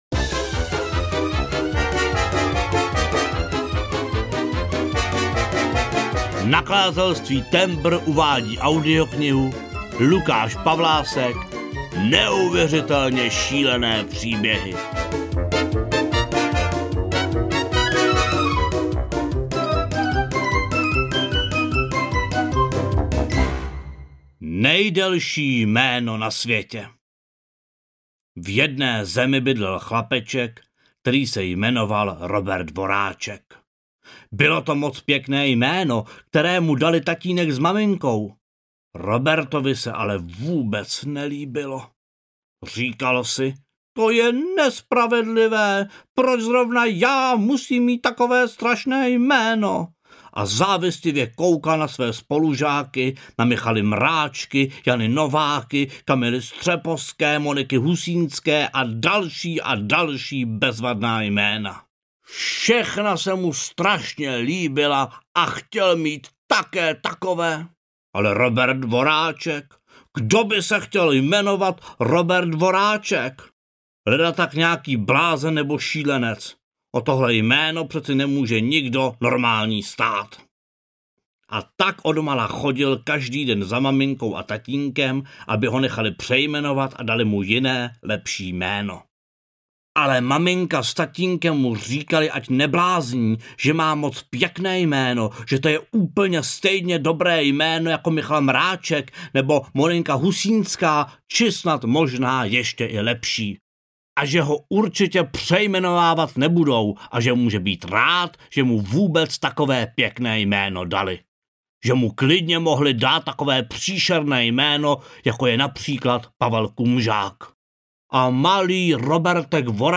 Ukázka z knihy
neuveritelne-silene-pribehy-audiokniha